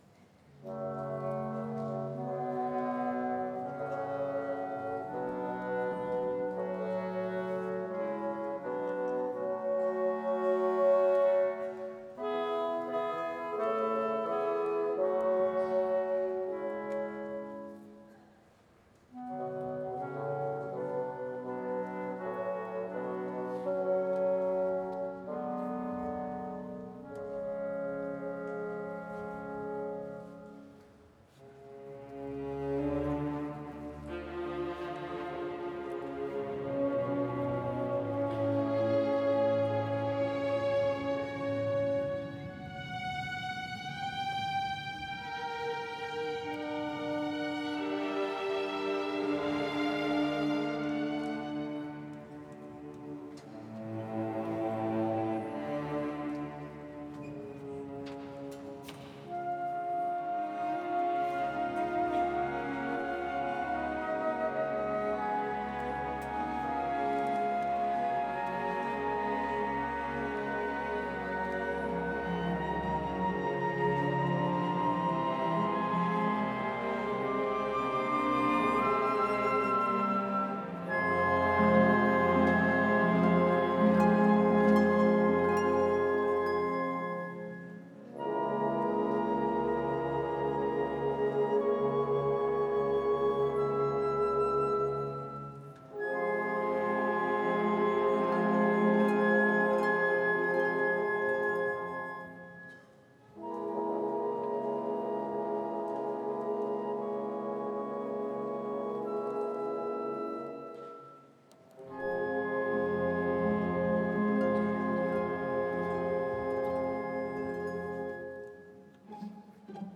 Η Εθνική Συμφωνική Ορχήστρα και η Χορωδία της ΕΡΤ, με αφορμή την Παγκόσμια Ημέρα Μουσικής, την Παρασκευή 21 Ιουνίου 2024, στις 21:00, παρουσιάζει, στο πλαίσιο του Φεστιβάλ Αθηνών Επιδαύρου, μια ξεχωριστή συναυλία με ελεύθερη είσοδο στο Ωδείο Ηρώδου Αττικού.